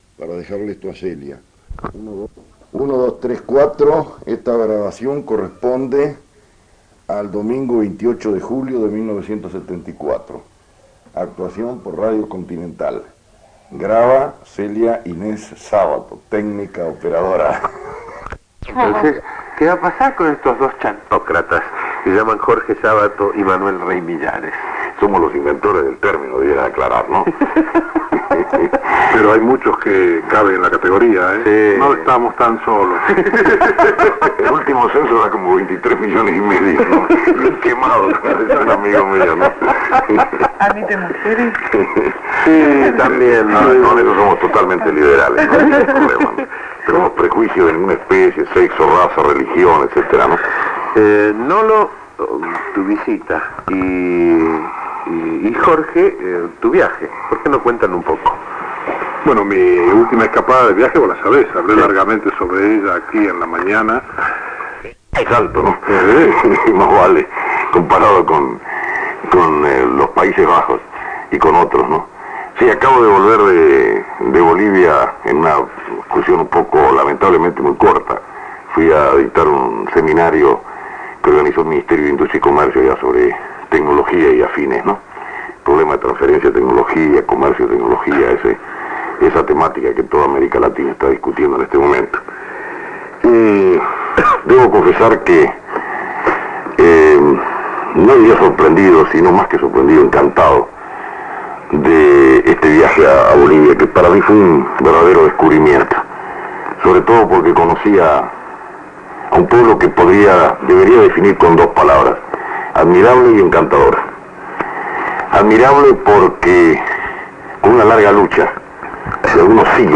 EL 28 de Julio de 1974 Miguel Brascó entrevista a Jorge A. Sabato en su programa "Las 12 horas de la radio" trasmitido por Radio Continental. Jorge A. Sabato cuenta sobre su viaje a Bolivia donde dictó un Seminario sobre Tranferencia de tecnología.